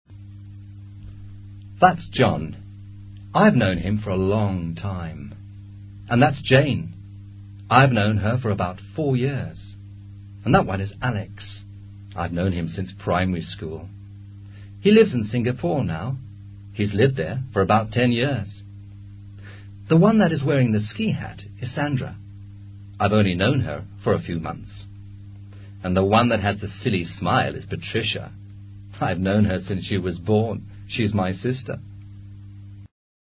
Archivo de sonido en el que un personaje habla sobre quienes aparecen retratados en una fotografía.
Lenguaje hablado